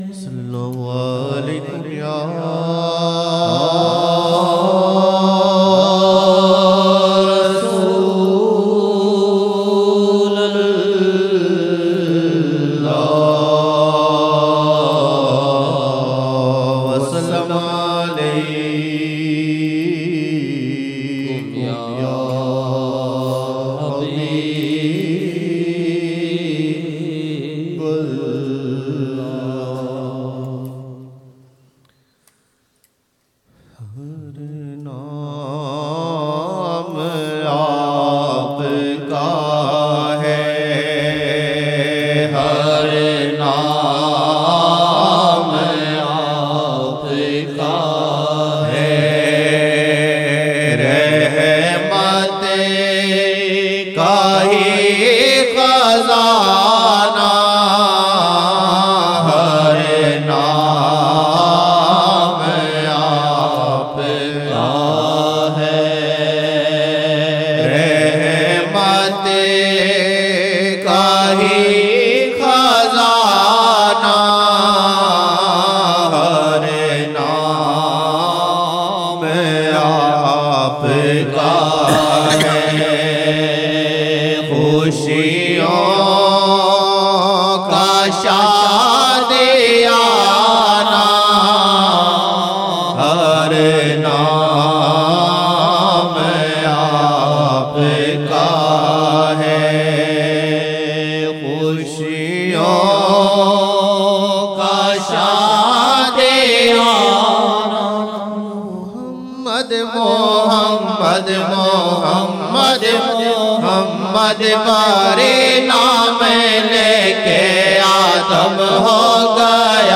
Har naam Aap (SAW) ka hai Rehmat ka hi khazana 2007-01-07 Fajr 07 Jan 2007 Old Naat Shareef